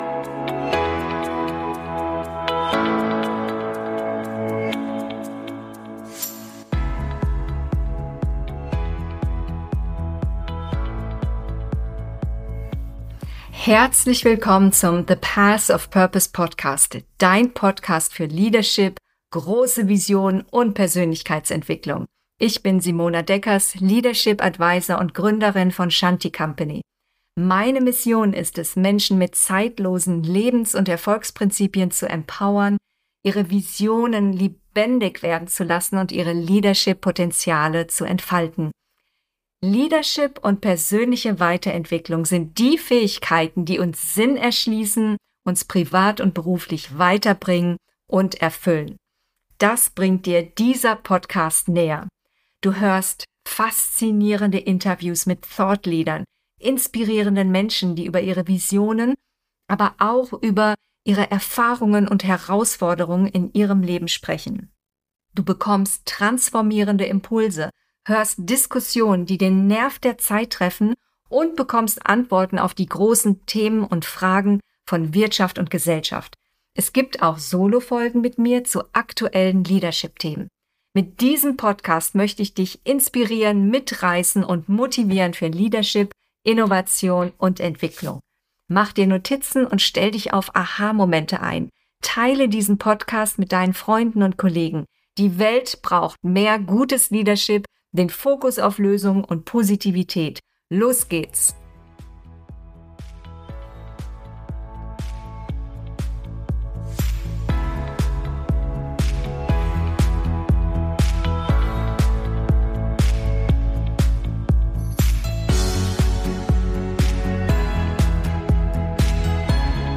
Von der Perfektion zur Authentizität: Personal Branding auf LinkedIn - Interview